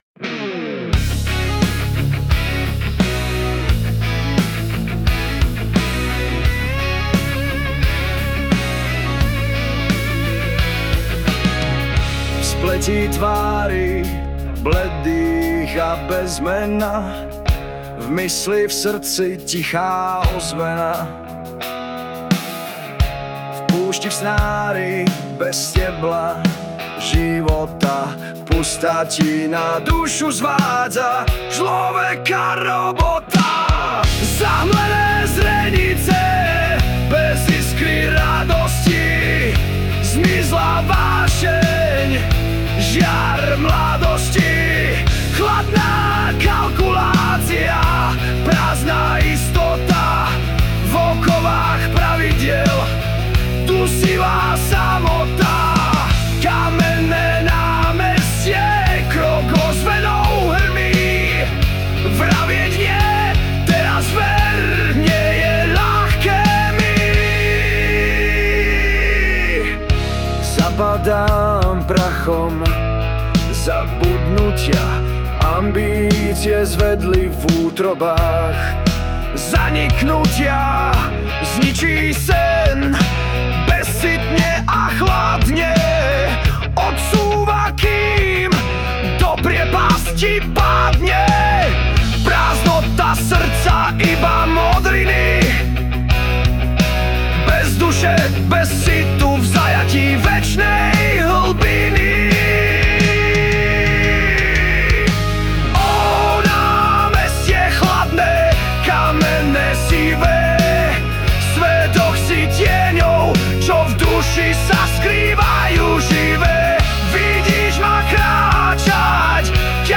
Hudba a spev AI
ďakujem taký malý protest song